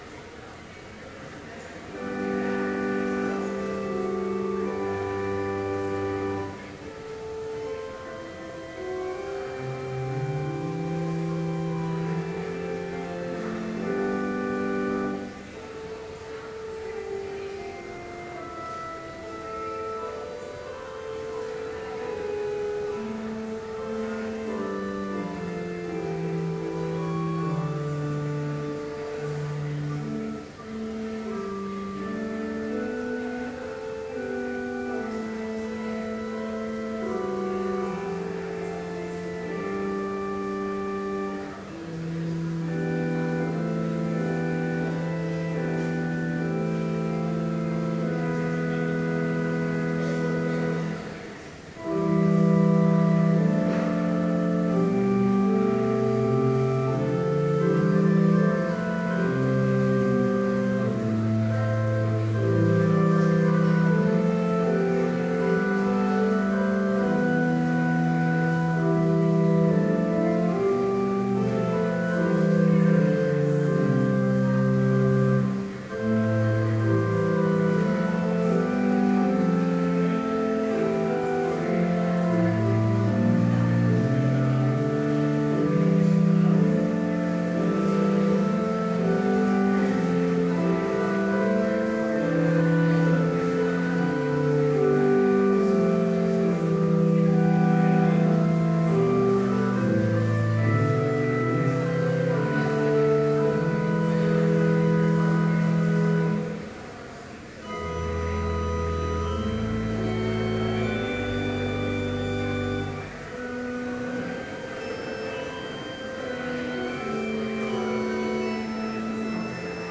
Complete service audio for Chapel - October 24, 2012